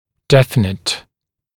[‘defɪnət][‘дэфинэт]ясный, точный; чётко выраженный; определенный